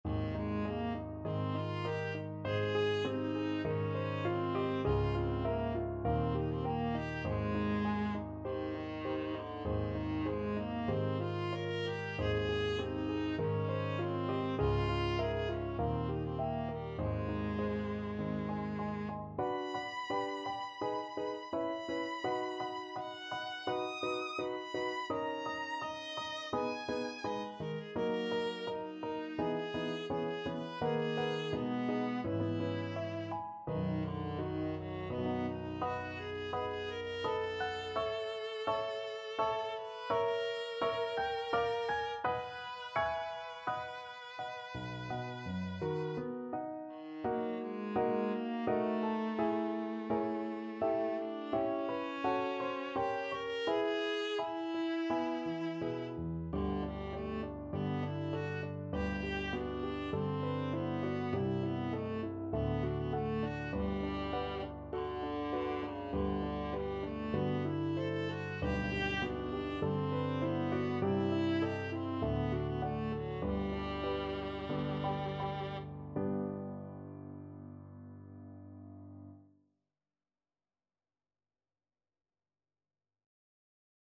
Viola
Moderato
Ab major (Sounding Pitch) (View more Ab major Music for Viola )
4/4 (View more 4/4 Music)
Classical (View more Classical Viola Music)